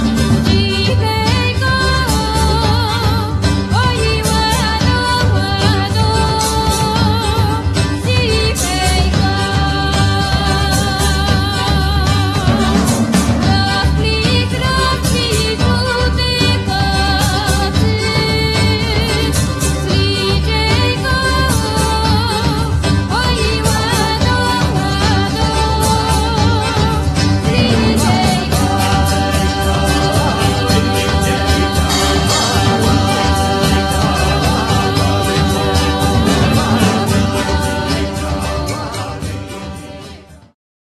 Zaprezentowali niezwykłe bogactwo brzmienia.
bębny, darabuka, instr. perkusyjne, chórki
kontrabas